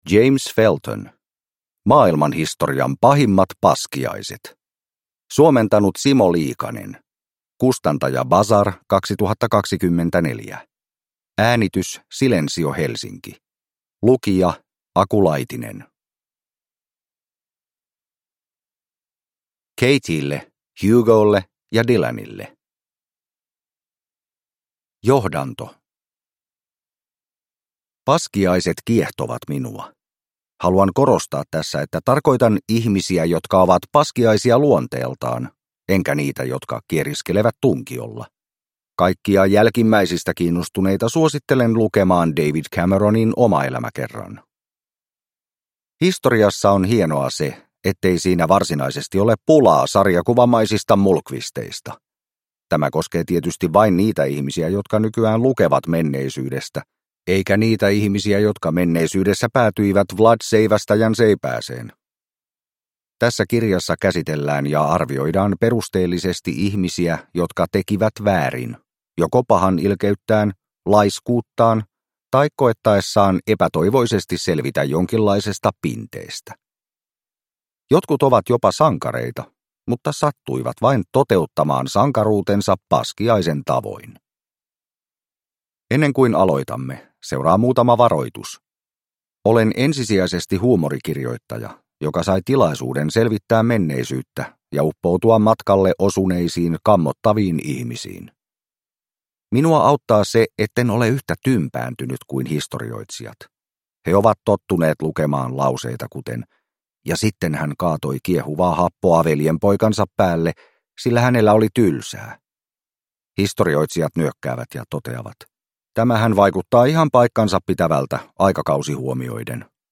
Maailmanhistorian pahimmat paskiaiset – Ljudbok